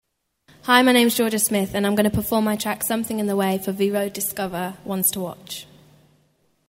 読み方
一時期BBCにてジョルヤと発音されたこともあったがカナ表記としてもジョルジャで確定。
Liveでの本人の自己紹介